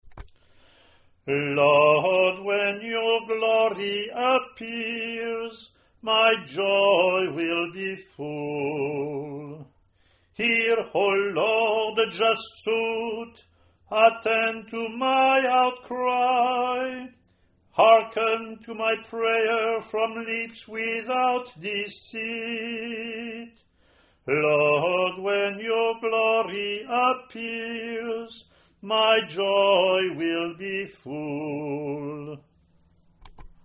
Responsorial :  (cantor sings once, schola repeats, cantor sings verses, schola leads assembly in response between verses).
Year C Responsorial (English)
ot32c-respons-eng.mp3